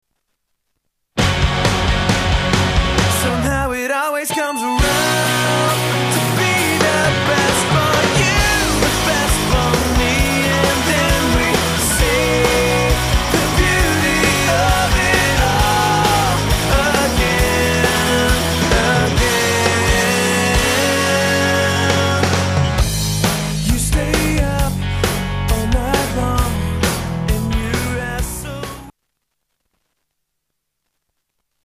STYLE: Rock
It does sound sort of '80s retro...